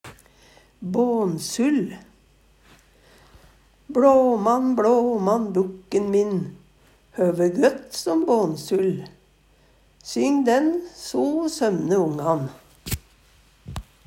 bånsull - Numedalsmål (en-US)